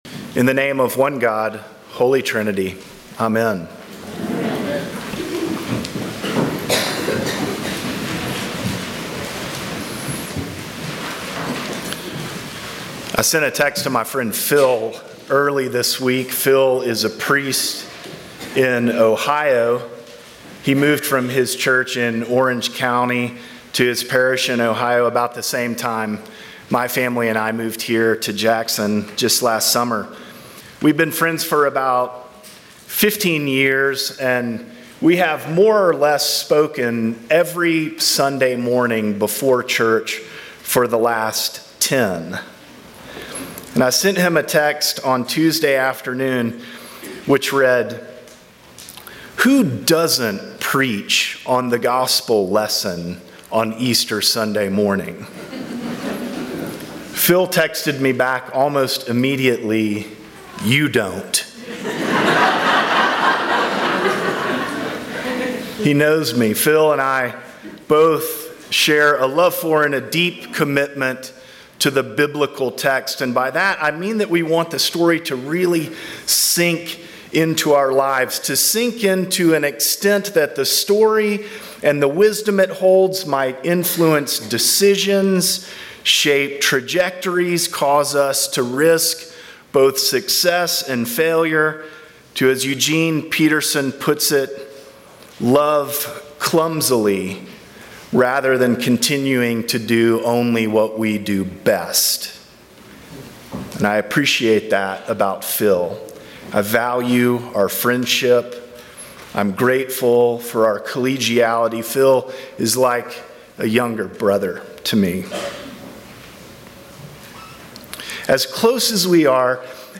Sermons from St. John's Episcopal Church Easter Sunday